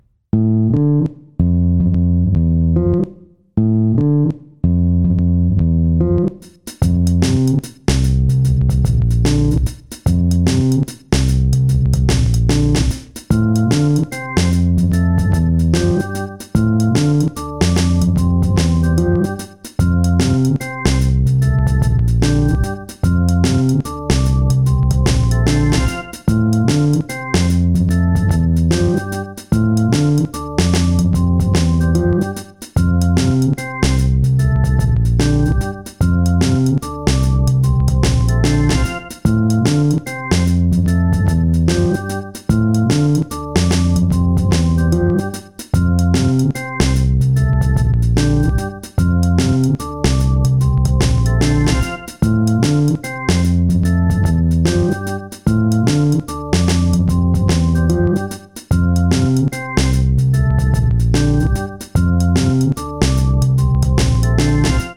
(1:04) Some sad but rythmic reggae for the saddy night